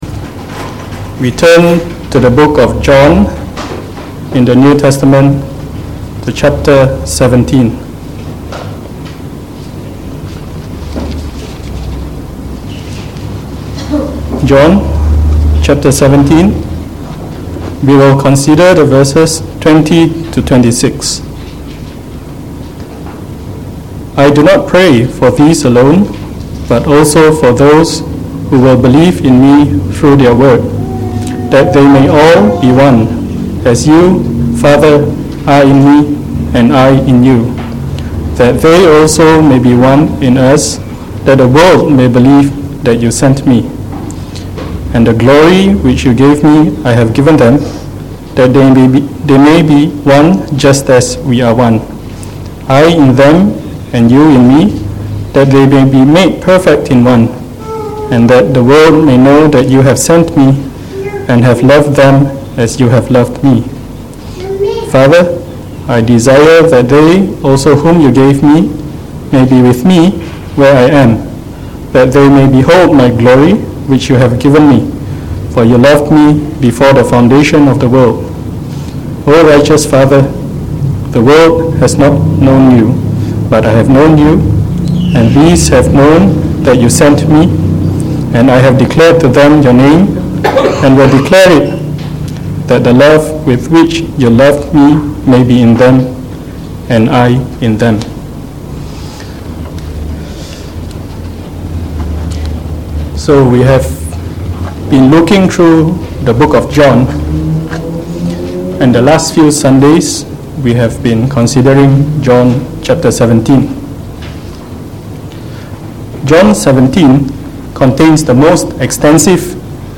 From our series on the Gospel of John delivered in the Evening Service